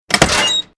CHQ_door_open.ogg